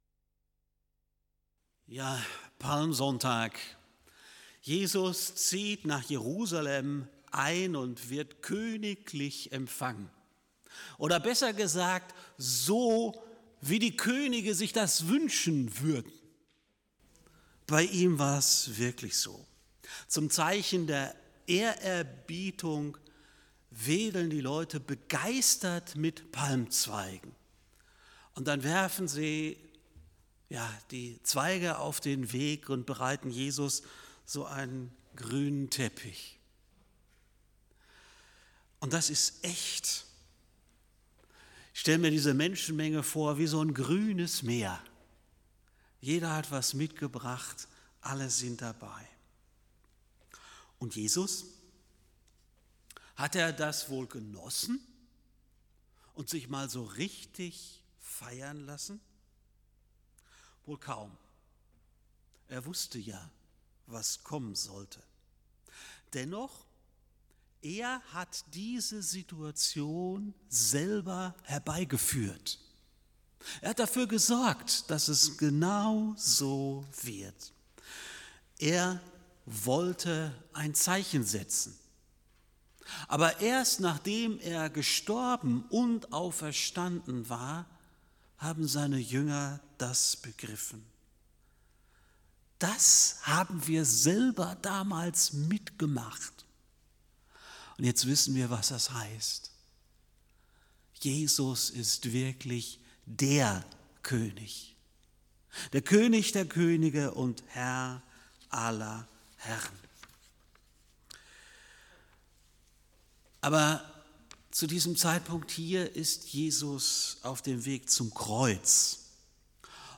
FeG Aschaffenburg - Predigt Podcast